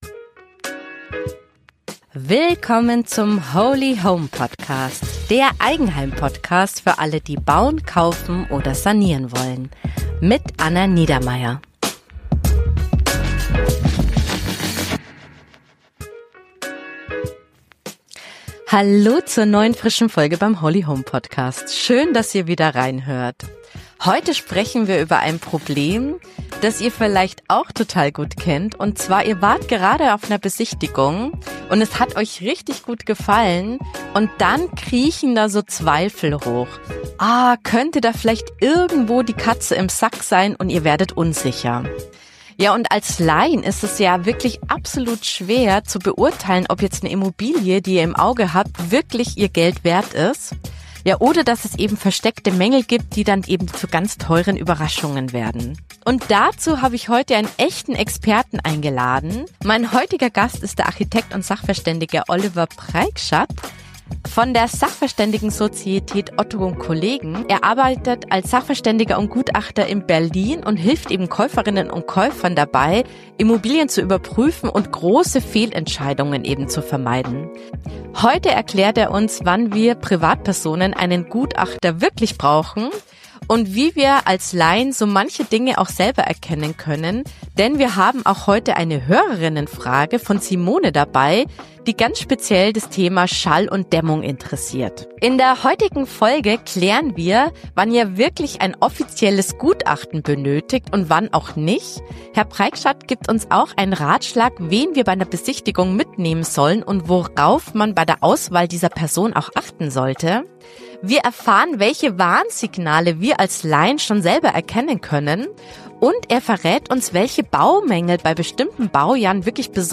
1 Darauf achte ich besonders bei Besichtigungen - Interview